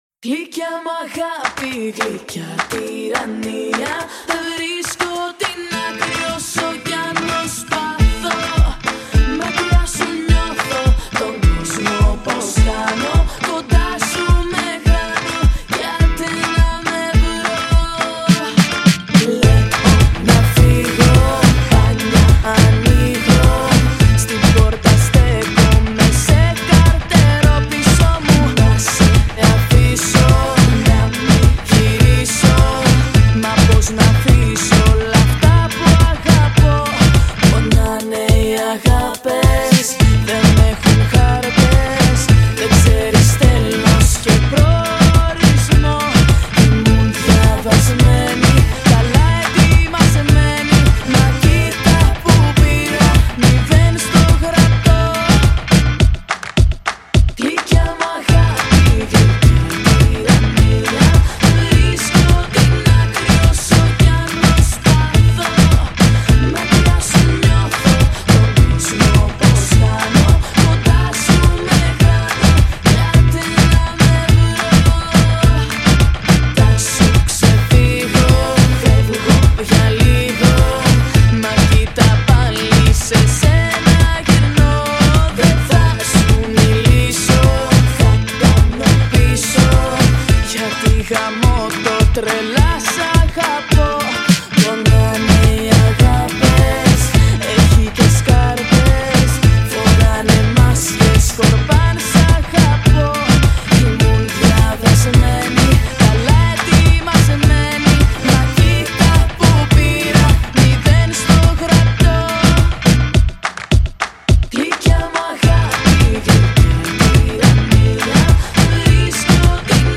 Ρυθμικό και απόλυτα καλοκαιρινό